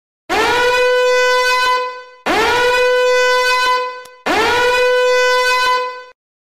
Тревожный сигнал, угроза